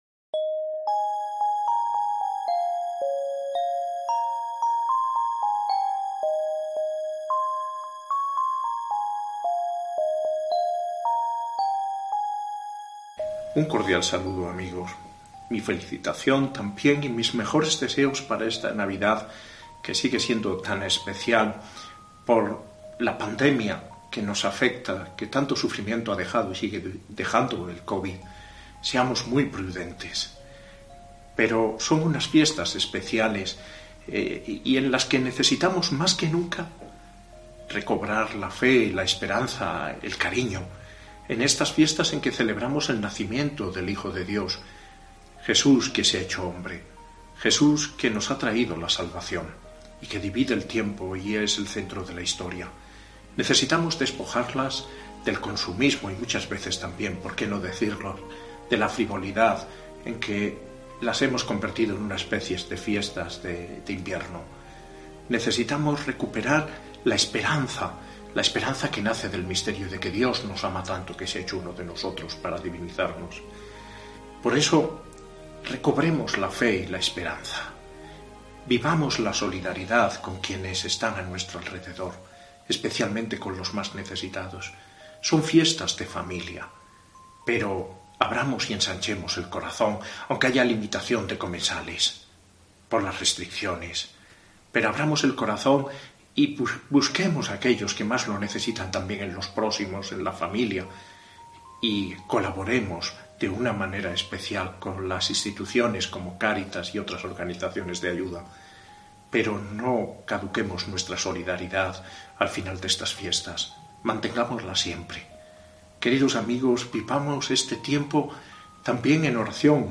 Mensaje de Navidad del Obispo de Ávila, José María Gil Tamayo